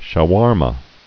(shə-wärmə) also shwar·ma (shwär-)